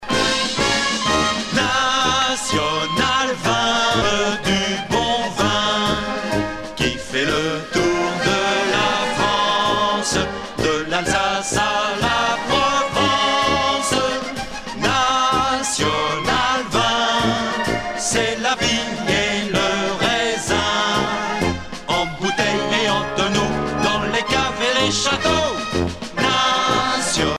danse : marche
Pièce musicale éditée